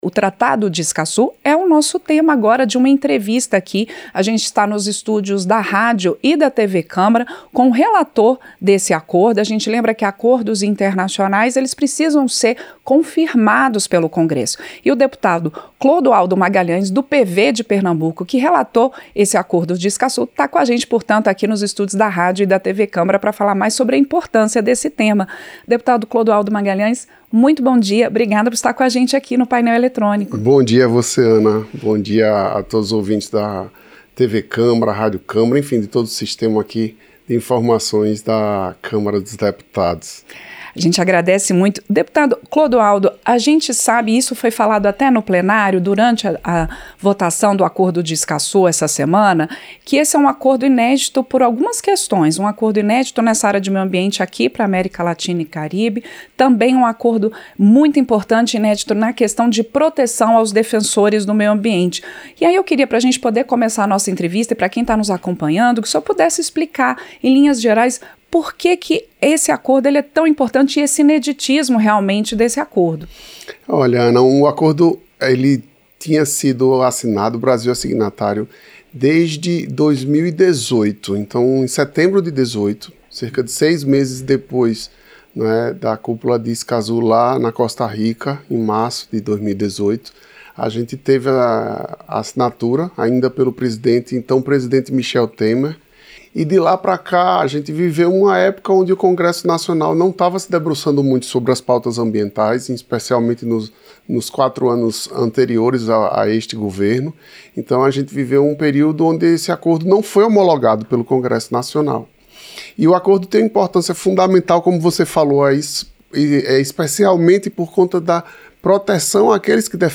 Entrevista - Dep. Clodoaldo Magalhães (PV-PE)